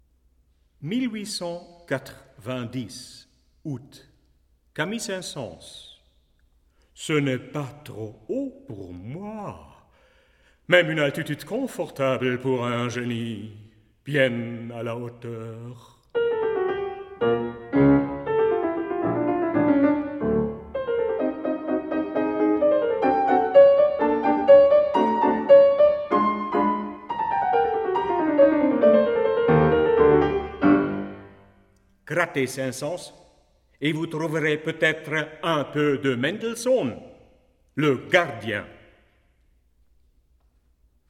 Albert Moeschinger: Camille Saint-Saëns, 1890 (piano)